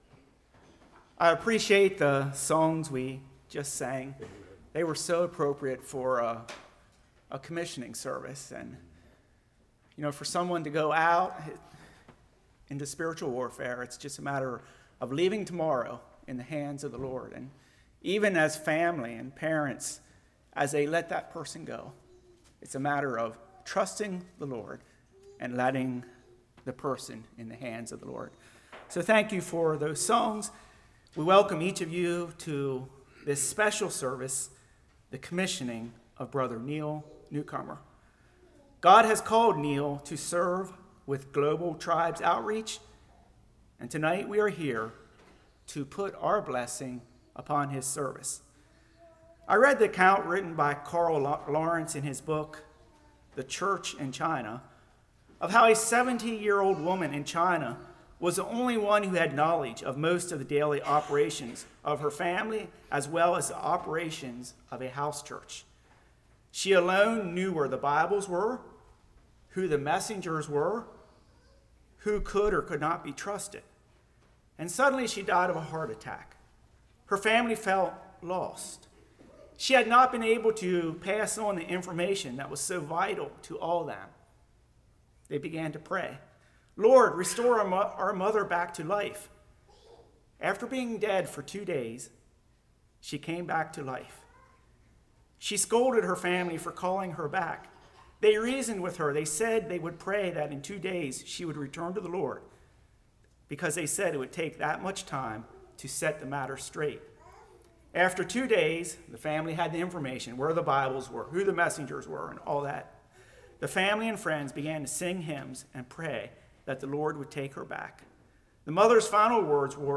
Matthew 28:18-20 Service Type: Commissioning Service Worship v Missions Discouragement Responsibility of senders « Who is Like the Lord?